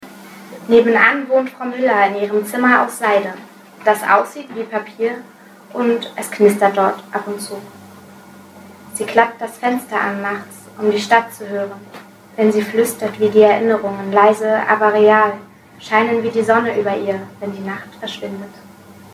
Gedichte
lesung.mp3